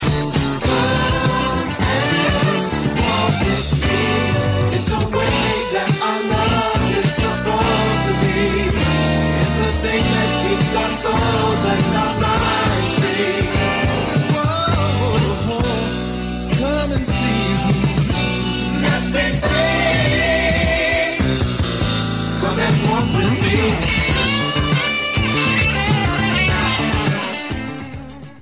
Background vocals, and guitar